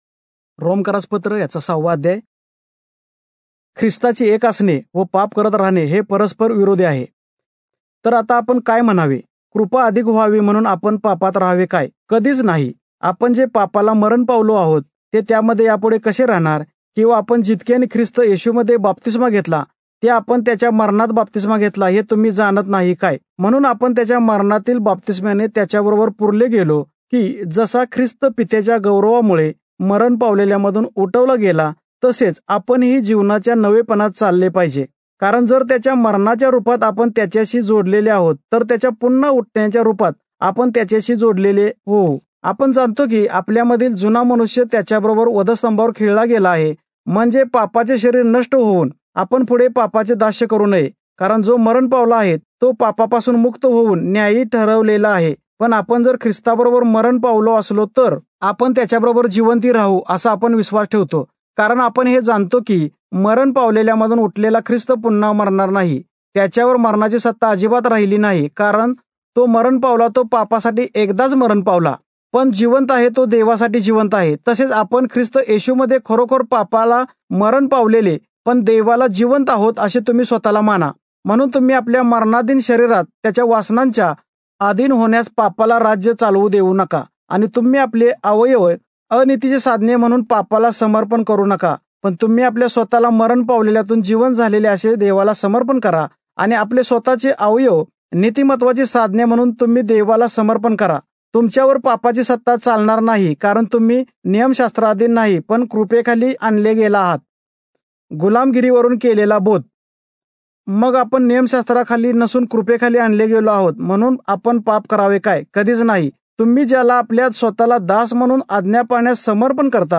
Marathi Audio Bible - Romans 5 in Irvmr bible version